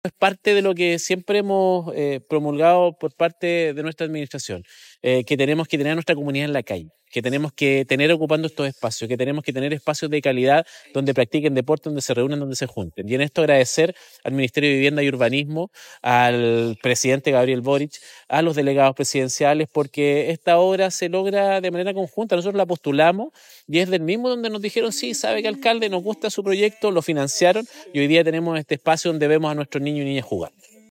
Cristian Herrera – Alcalde Monte Patria